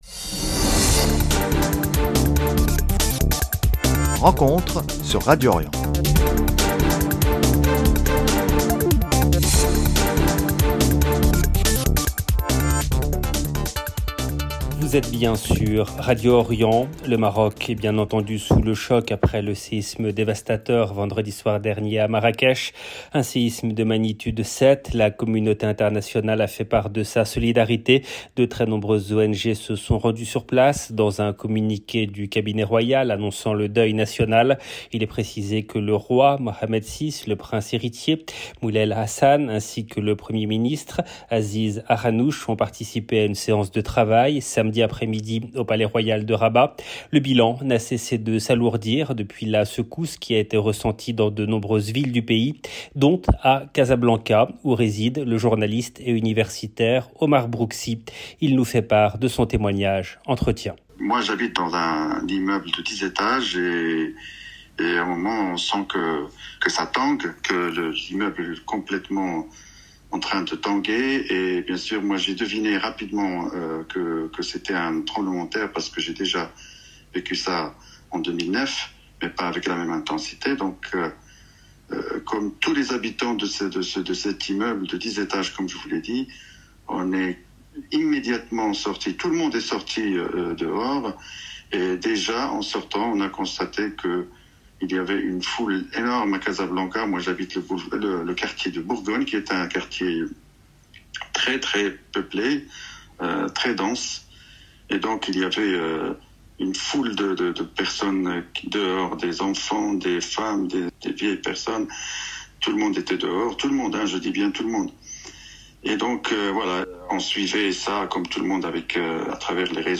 dimanche 10 juin 2023 ENTRETIEN CONSACRE AU SEISME AU MAROC Le Maroc est sous le choc après le séisme dévastateur vendredi soir dernier à Marrakech.